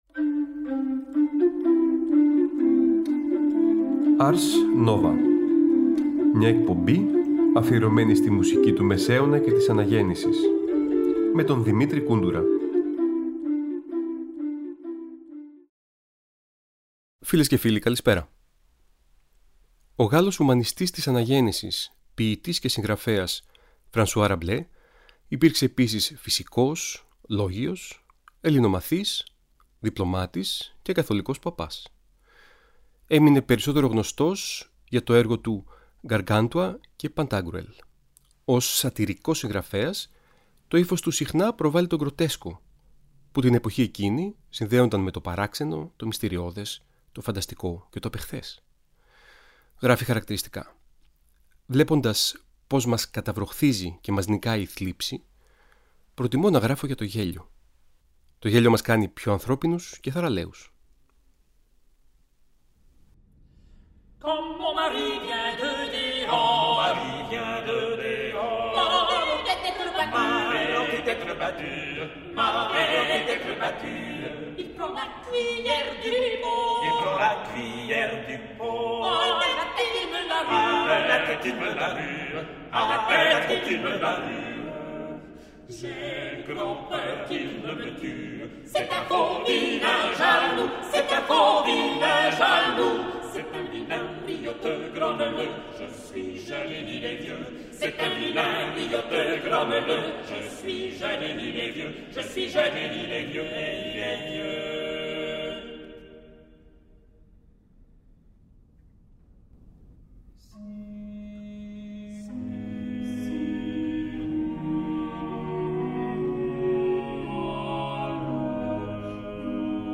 Ένα ταξίδι στη Γαλλία της Αναγέννησης μέσα από την πολυφωνία της εποχής.